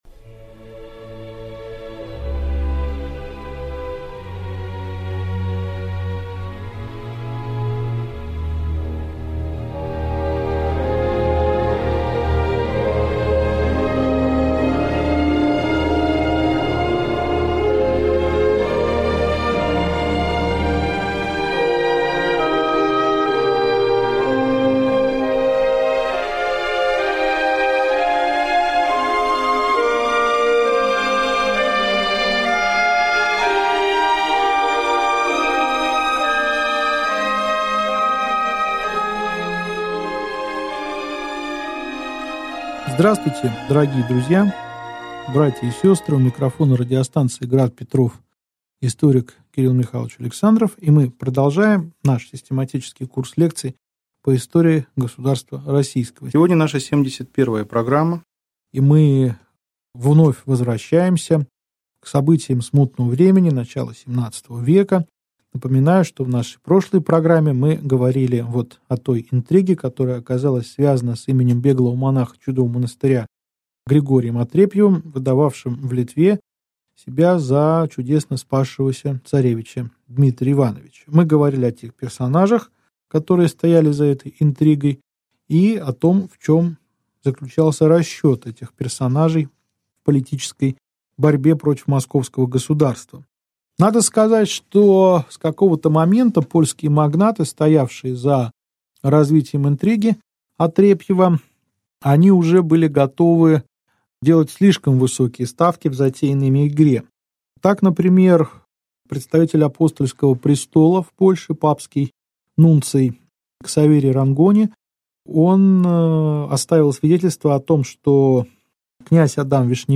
Аудиокнига Лекция 71. Смерть Бориса Годунова. Его сын Федор. Начало Смуты | Библиотека аудиокниг